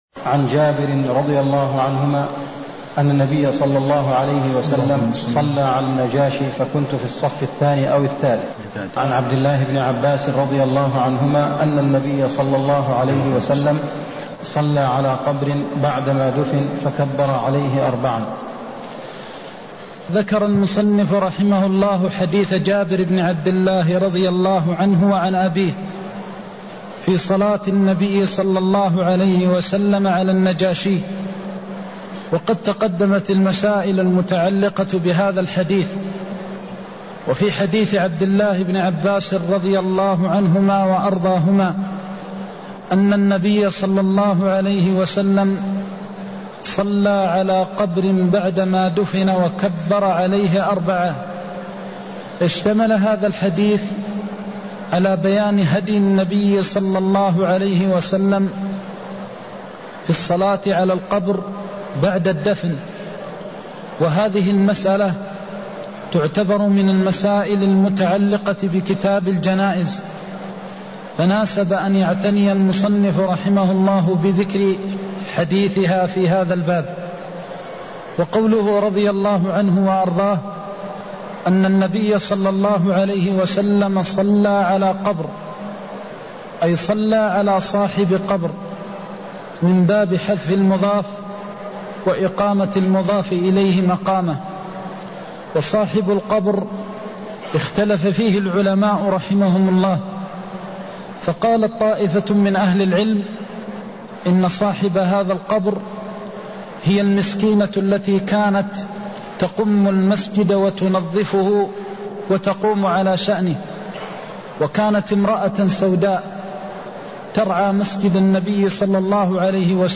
المكان: المسجد النبوي الشيخ: فضيلة الشيخ د. محمد بن محمد المختار فضيلة الشيخ د. محمد بن محمد المختار الصلاة على الميت بعد دفنه (151)